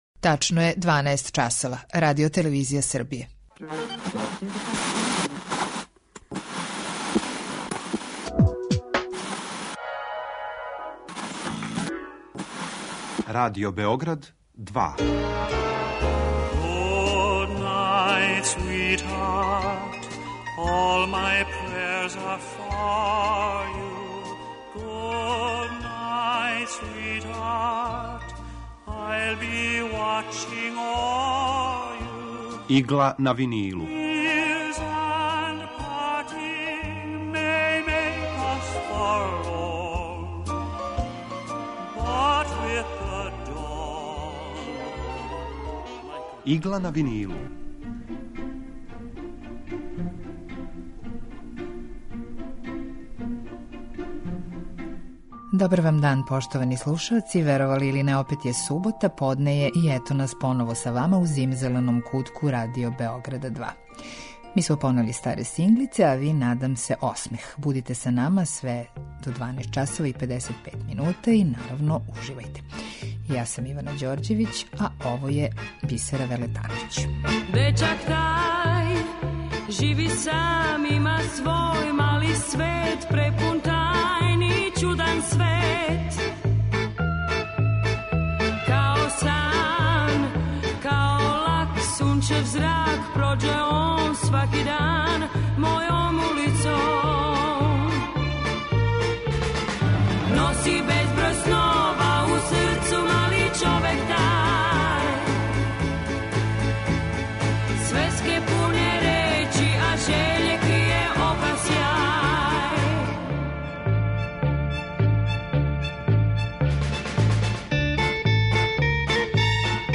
У Игли на винилу представљамо одабране композиције евергрин музике од краја 40-их до краја 70-их година 20. века. Свака емисија садржи кроки композитора / извођача и рубрику Два лица једног хита (две верзије исте композиције)